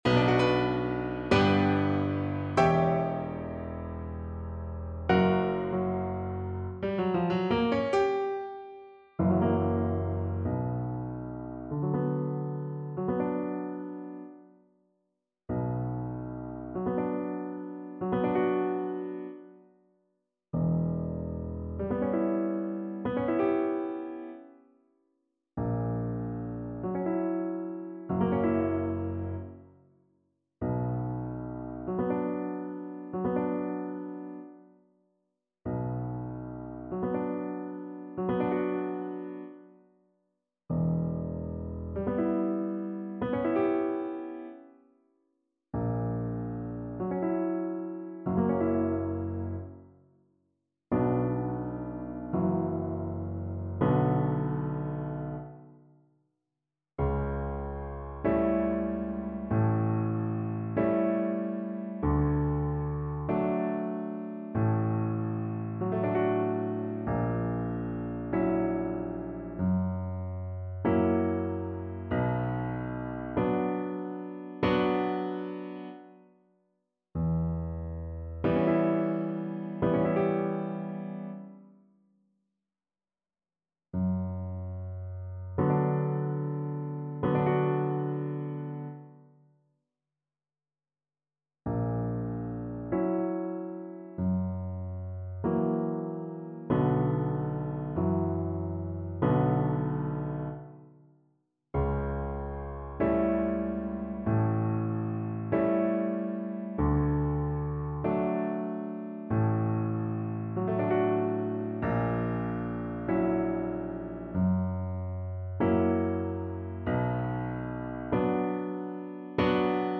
Largo = c.69
2/4 (View more 2/4 Music)
Classical (View more Classical Double Bass Music)